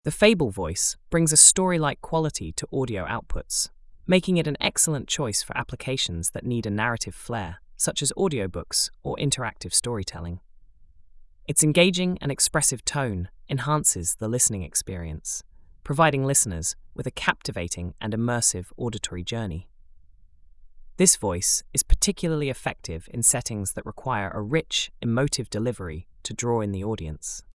The “Fable” voice brings a story-like quality to audio outputs, making it an excellent choice for applications that need a narrative flair, such as audiobooks or interactive storytelling. Its engaging and expressive tone enhances the listening experience, providing listeners with a captivating and immersive auditory journey.